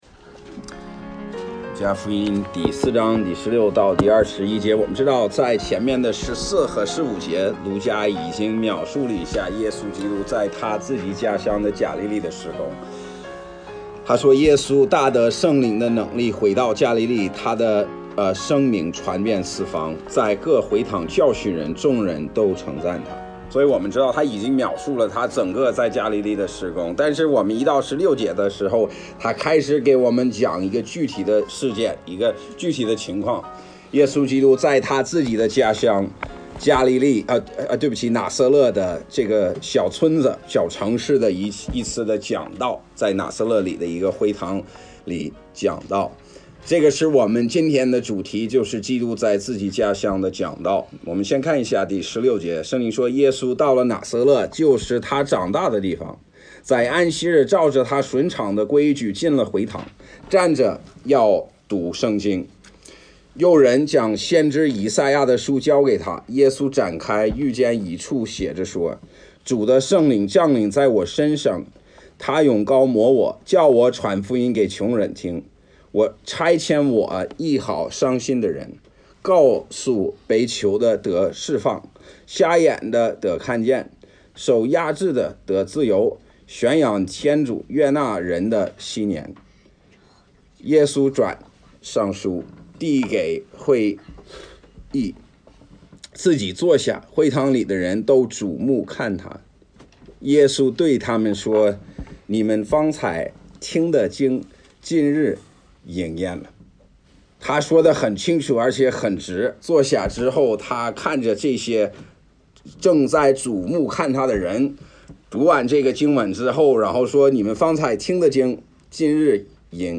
讲道 – 哈尔滨权胜浸信教会